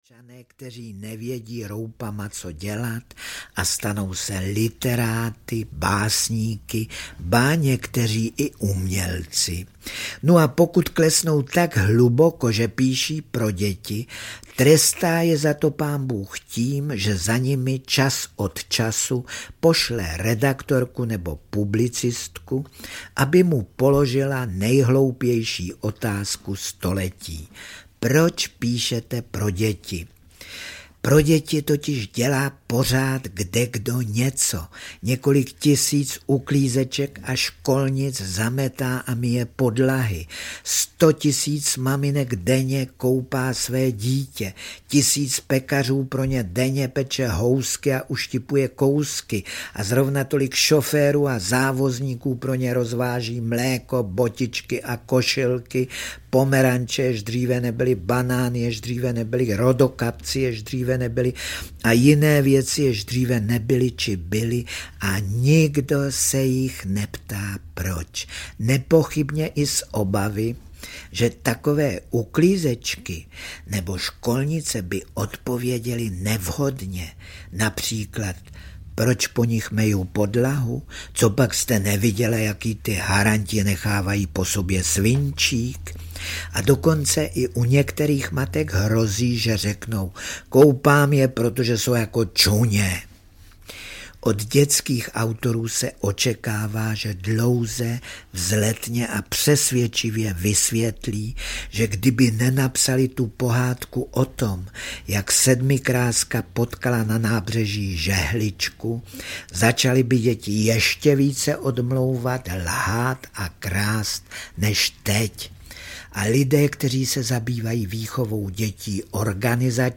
Originální nahrávky Františka Nepila z devadesátých let Unikátní kolekce pěti titulů mluveného slova pro dospělé doplněných albem pohádek pro vnoučky.
Audio knihaFrantišek Nepil - Kolekce audioknih
Ukázka z knihy
• InterpretJana Hlaváčová, Luděk Munzar, Petr Nárožný, František Nepil